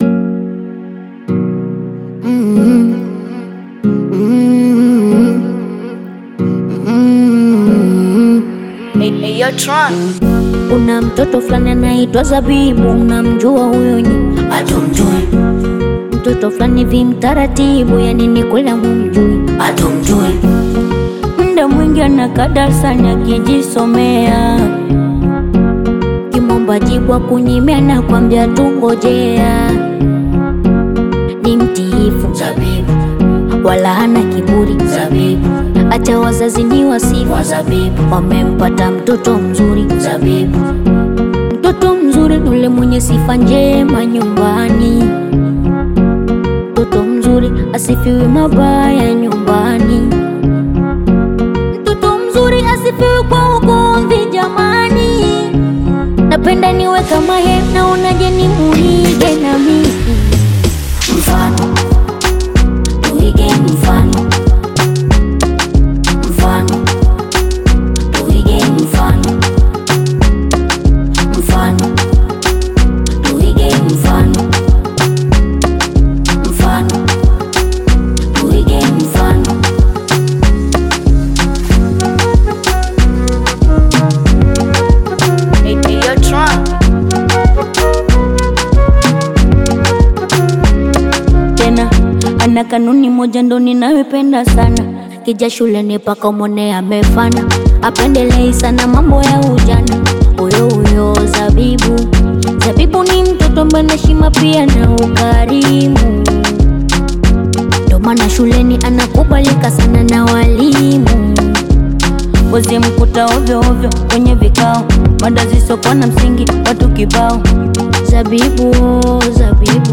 Bongo Flava music track
Tanzanian Bongo Flava